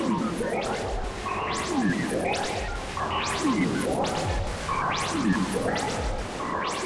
STK_MovingNoiseC-140_03.wav